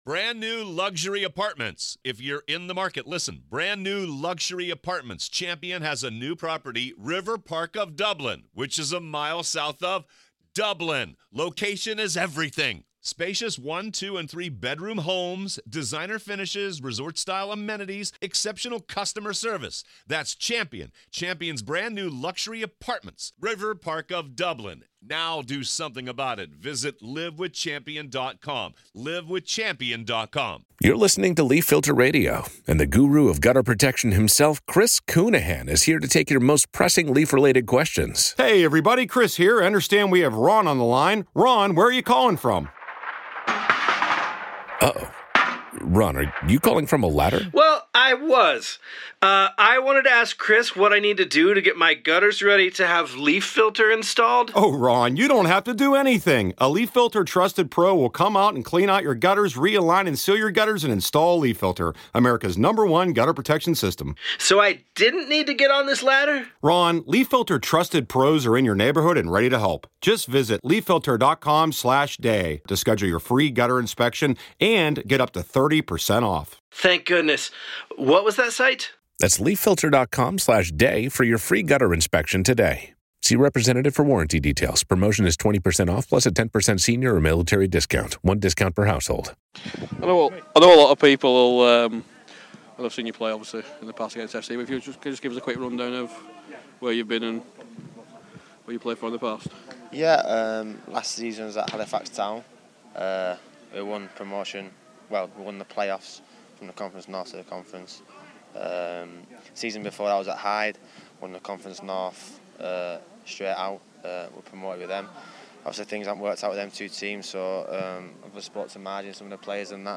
Pre Season Interview